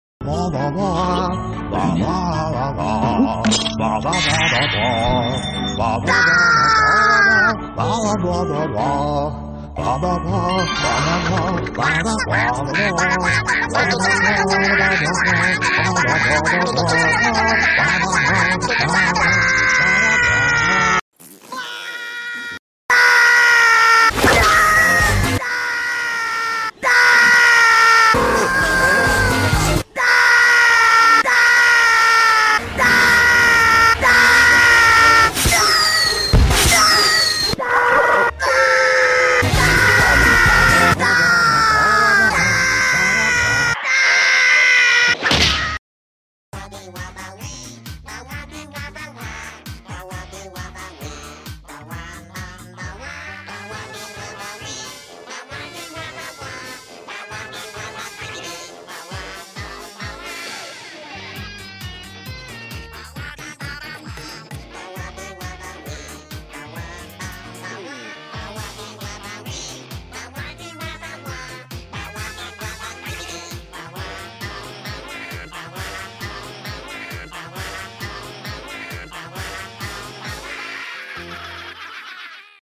🐇 Berteriak Rabbids invasion sound effects free download